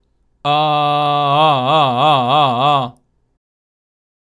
次に、グーの声で「え」の音、もしくは「あ」の音を伸ばしながら、見本音声のように5回発声します。
※見本音声(「あ」の音)
h01_vibrato_G_a_slow.mp3